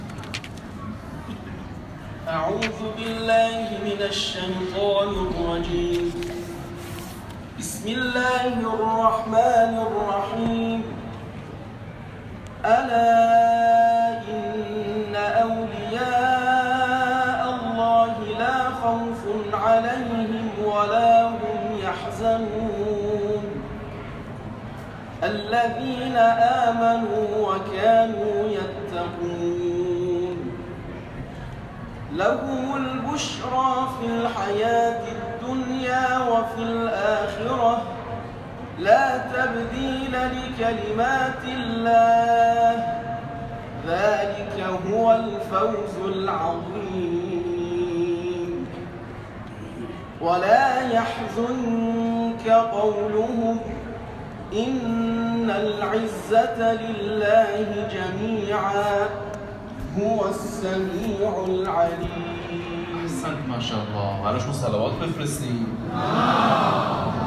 تلاوت
در محفل انس با قرآن آستان امامزاده هلال‌بن‌علی(ع)